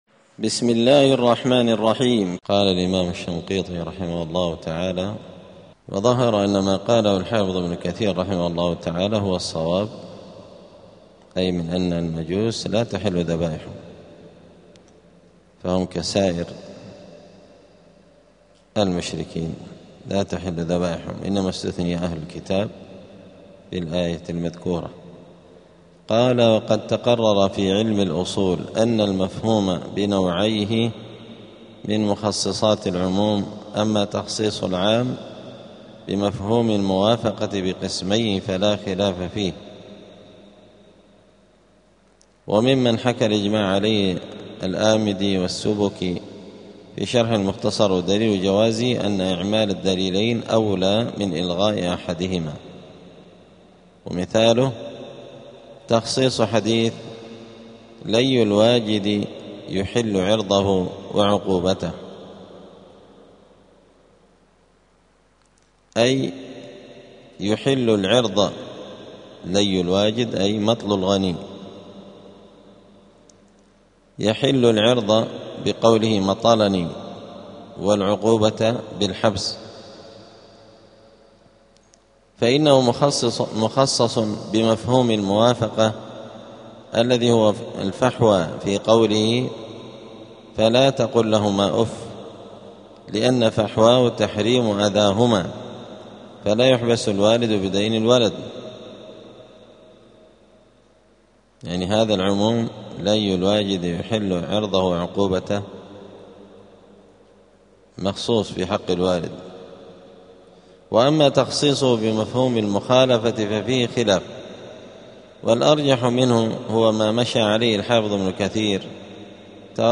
*الدرس الثالث الثلاثون (33) {سورة المائدة}.*